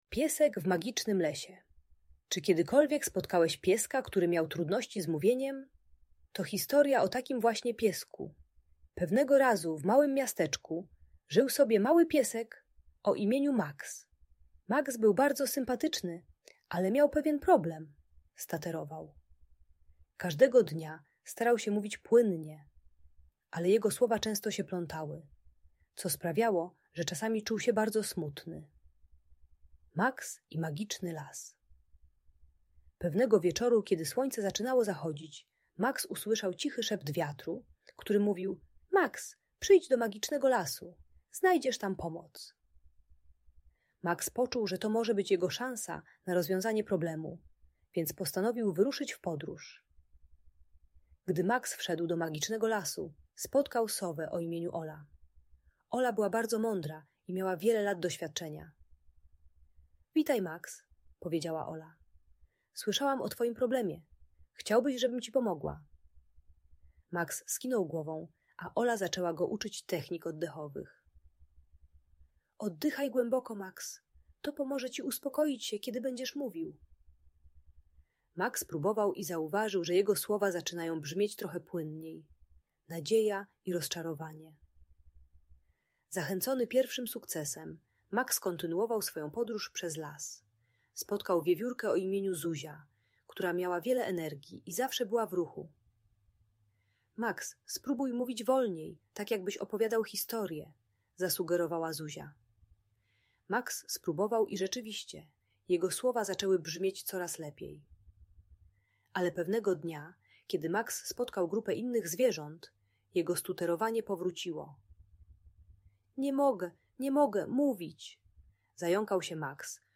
Piesek w Magicznym Lesie - Niepokojące zachowania | Audiobajka